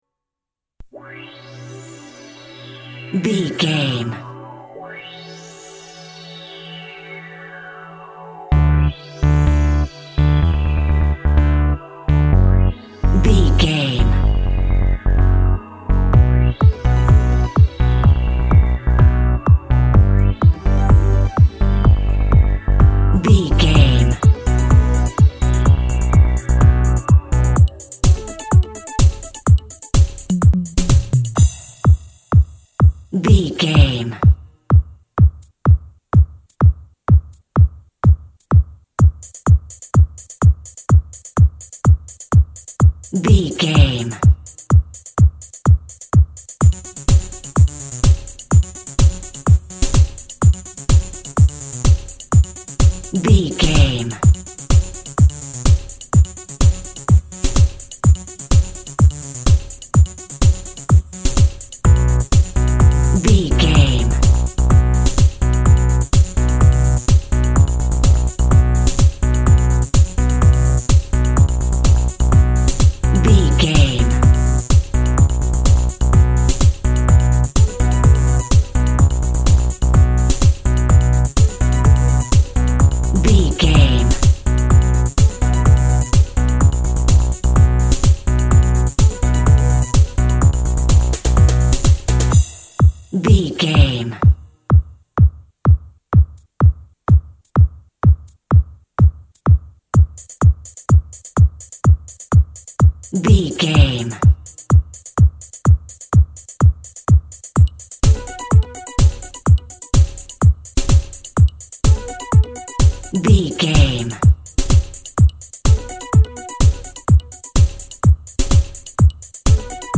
Fast paced
Aeolian/Minor
aggressive
dark
groovy
strings
synthesiser
drum machine
techno
industrial
glitch
synth lead
synth bass
electronic drums
Synth Pads